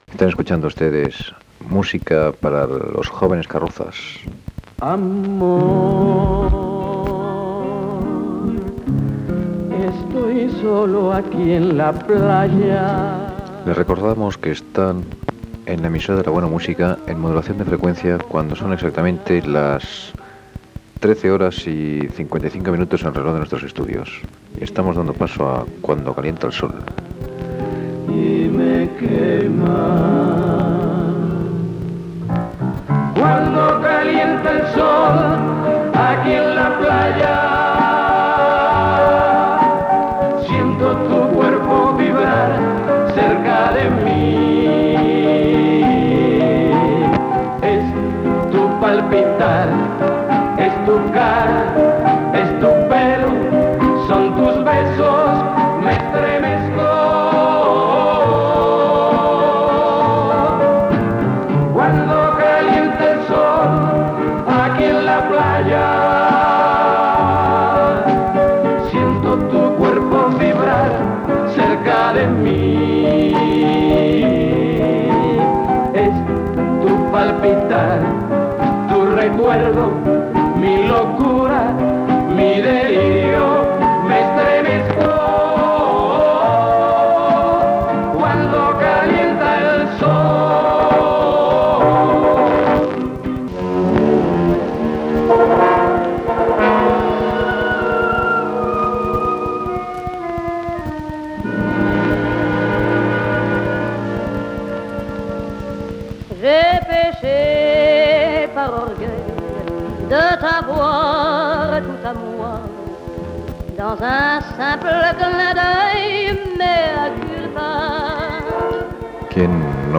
72f7c32f3e301e3bf70de6ca04abb5a74b87681e.mp3 Títol Emisora de la Buena Música Emissora Emisora de la Buena Música Titularitat Tercer sector Tercer sector Musical Descripció Identificació, hora i tema musical per a joves "carrosses".